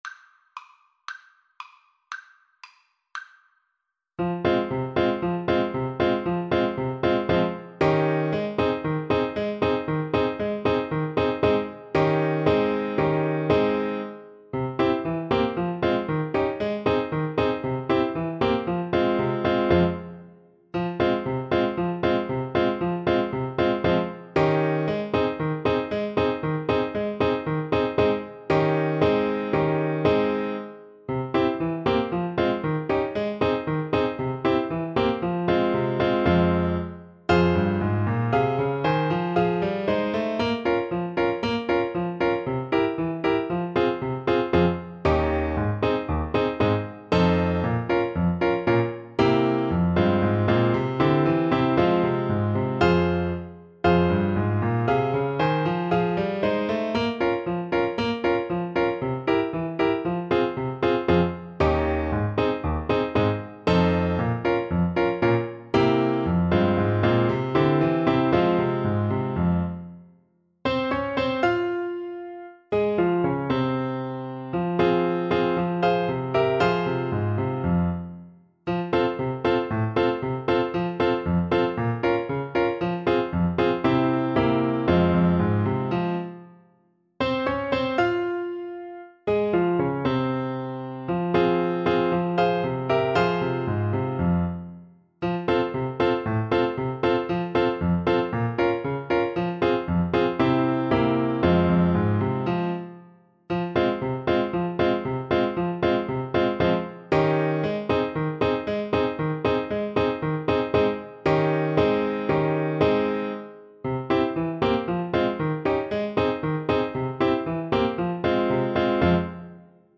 Play (or use space bar on your keyboard) Pause Music Playalong - Piano Accompaniment Playalong Band Accompaniment not yet available reset tempo print settings full screen
F minor (Sounding Pitch) C minor (French Horn in F) (View more F minor Music for French Horn )
Allegro =c.116 (View more music marked Allegro)